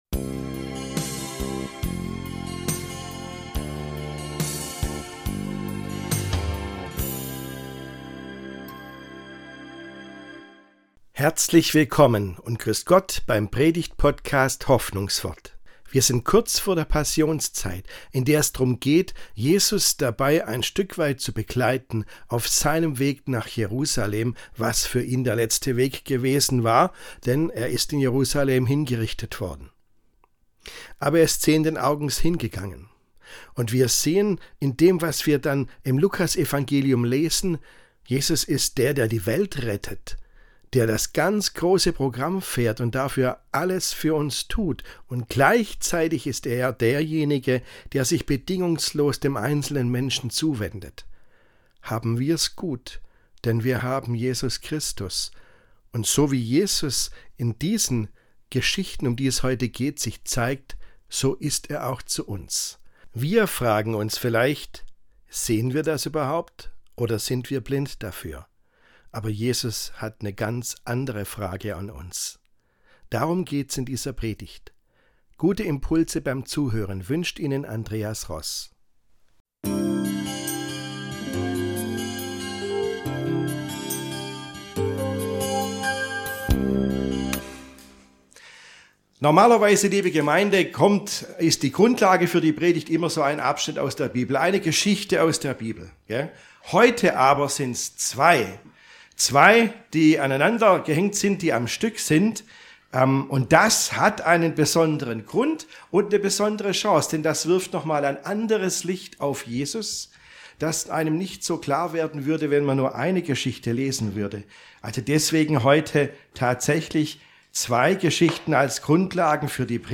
Selbst ein Blinder sieht mehr ~ Hoffnungswort - Predigten